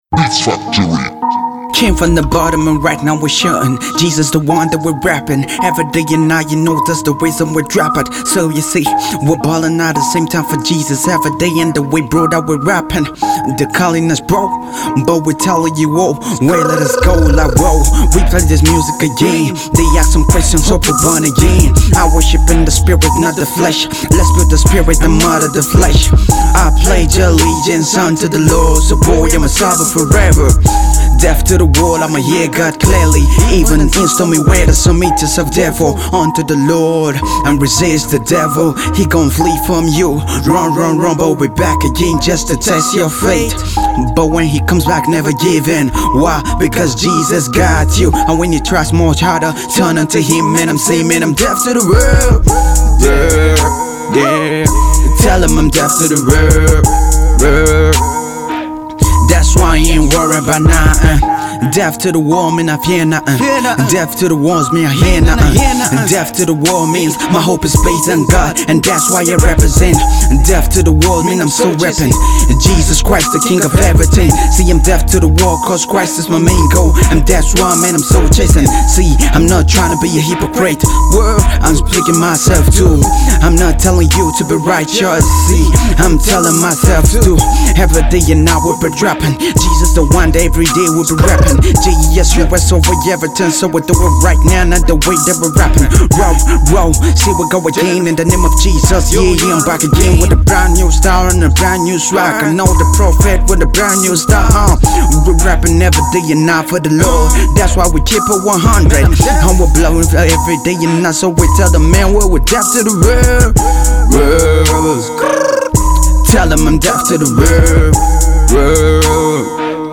gospel rapper